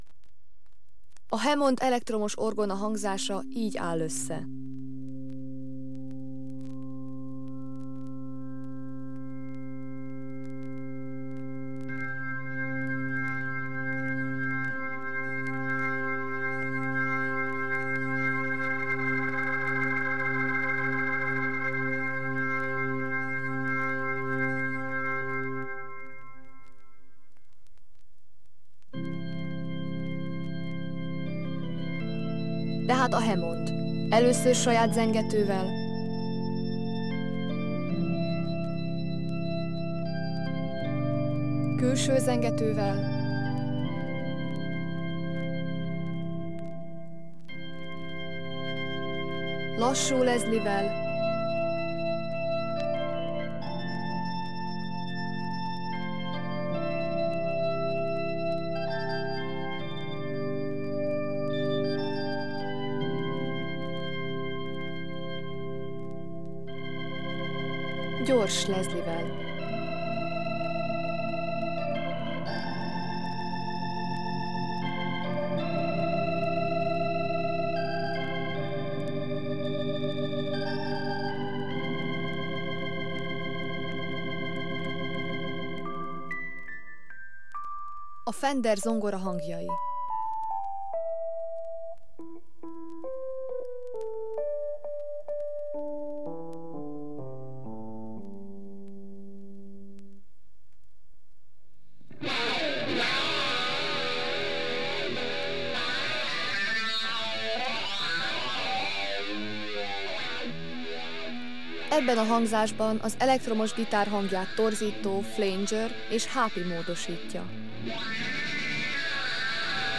Adathordozó Vinyl kislemez ( 17 cm ), 45-ös percenkénti fordulat
Lemezjátszó Akai AP-Q310